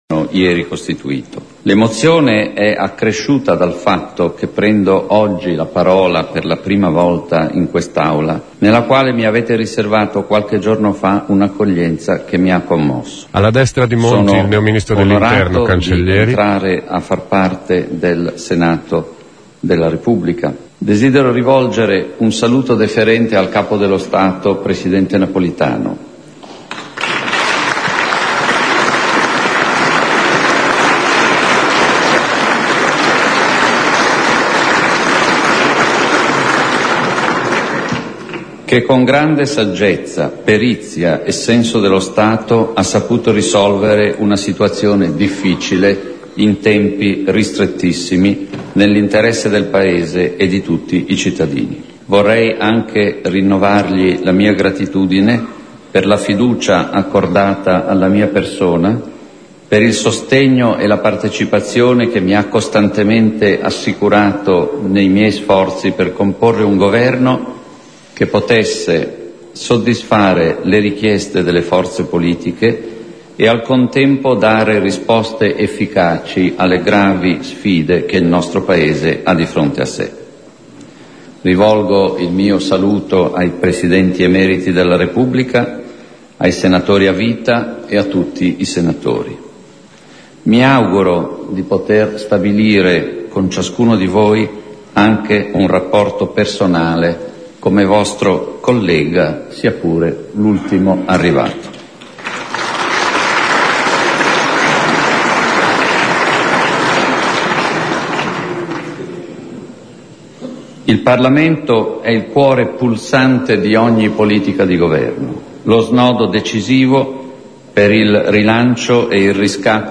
Ecco il discorso del premier Mario Monti al Senato. In 44 minuti Monti ha delineato le linee programmatiche di quello che ha definito un governo di impegno nazionale. Ha parlato di riforma delle pensioni, reintroduzione dell'Ici, alleggerimento della tassazione sul lavoro.